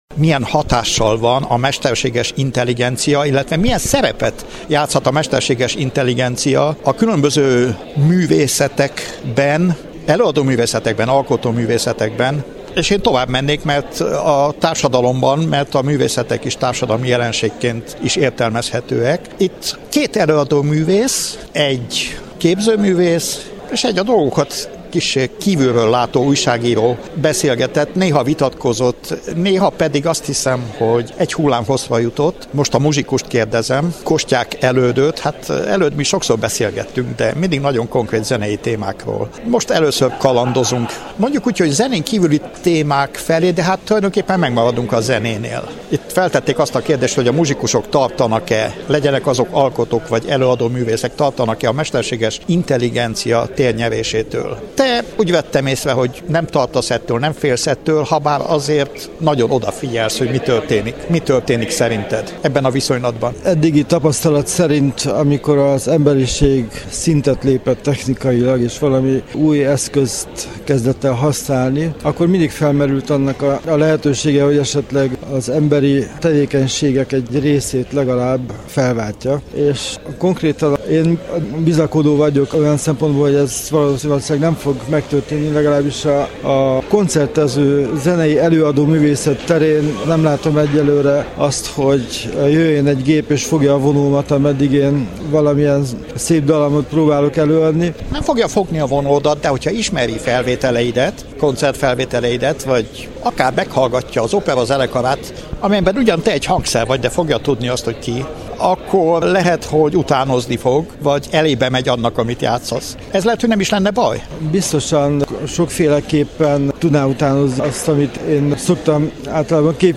Kerekasztal-beszélgetésen jártunk a 16. Kolozsvári Magyar Napokon.
Az eseményt követően kértük mikrofon elé a meghívottakat.
Mesterseges-intelligencia-kerekasztal.mp3